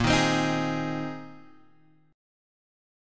A#9sus4 chord {6 6 x 5 4 4} chord